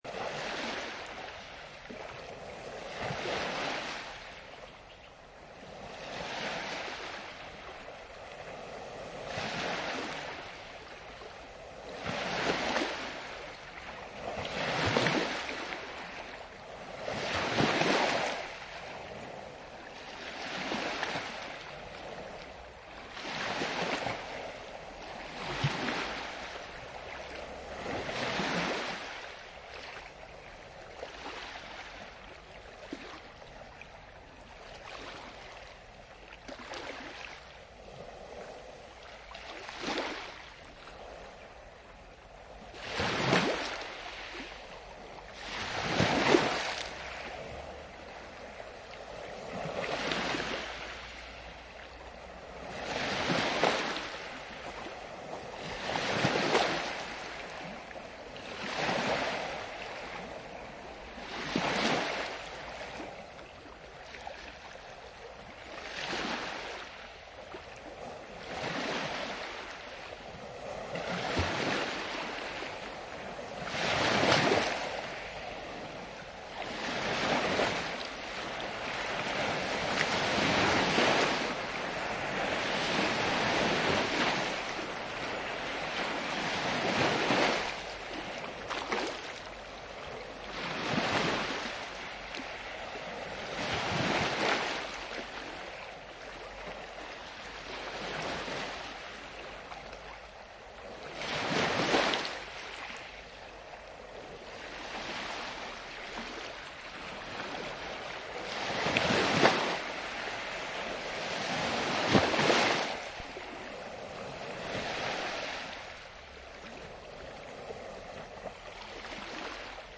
Bande sonore sur le bruit des vagues pour vous plonger dans ces poèmes.
mer.mp3